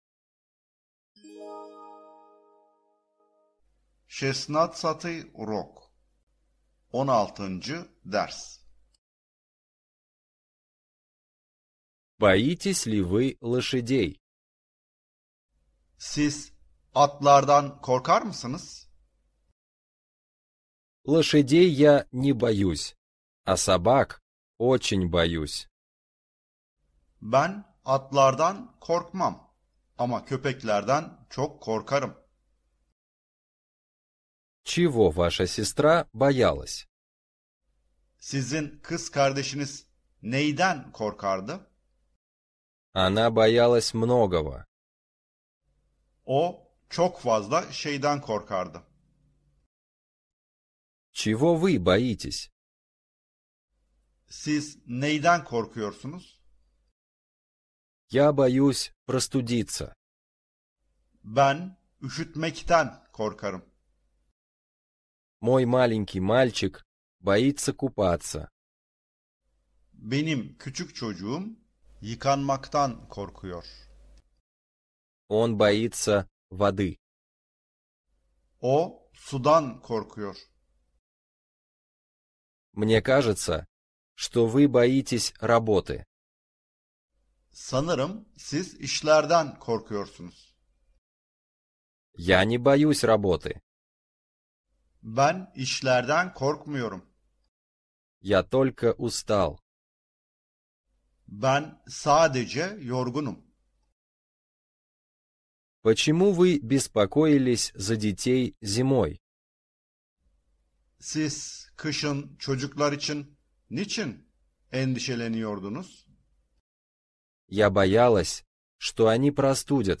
1. ÜRÜN (24 Derste Sesli Rusça Eğitim)
Her dersten ilk 3 dakikayı sizlerle paylaşıyoruz. Konular Rusça başlangıç seviyesinden başlayarak, orta ve ileri seviyelere kadar ilerliyor.